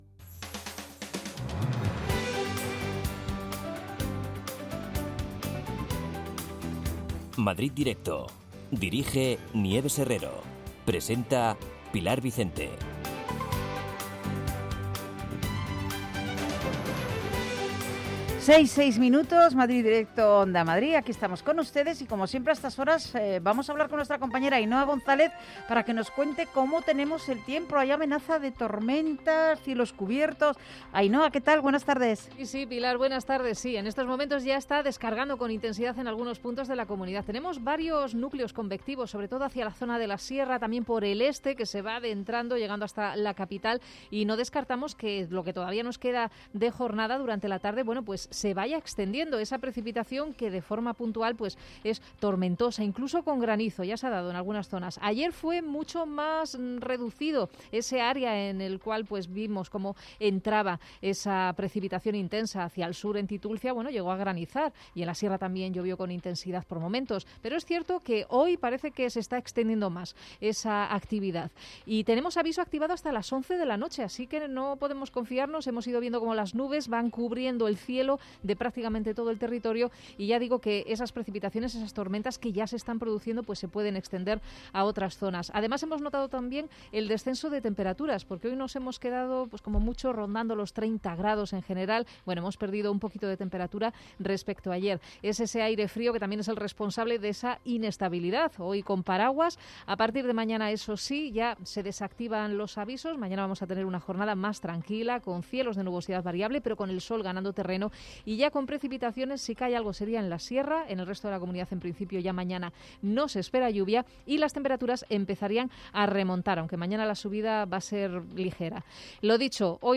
Nieves Herrero se pone al frente de un equipo de periodistas y colaboradores para tomarle el pulso a las tardes. Cuatro horas de radio donde todo tiene cabida.